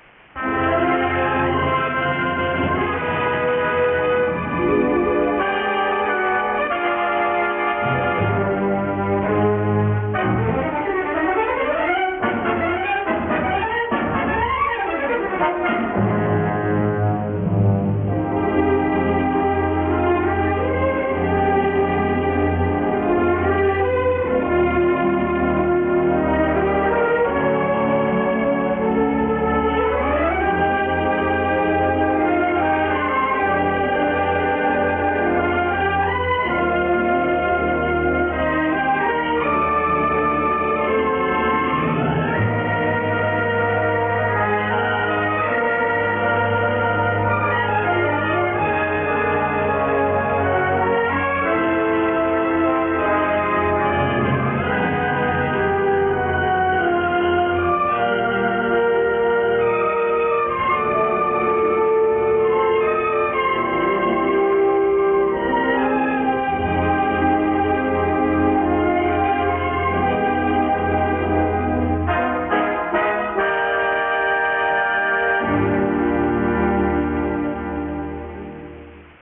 Track Music